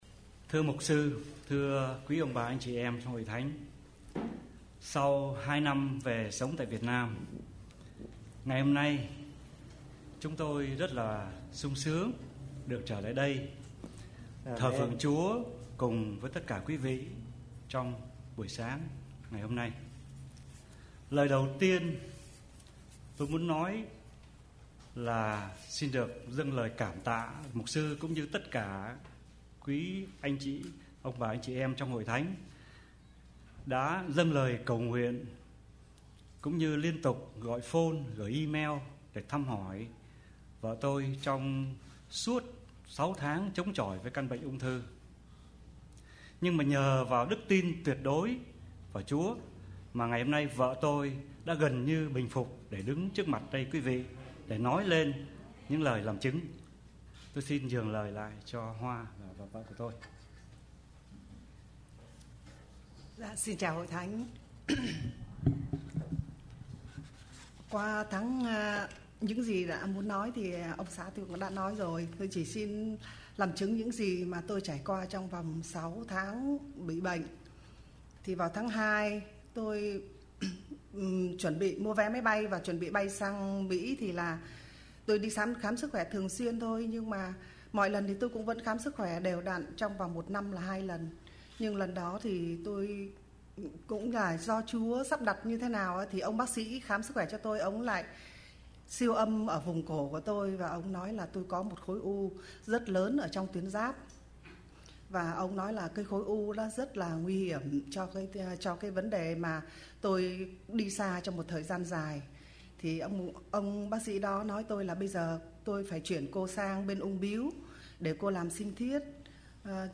Làm Chứng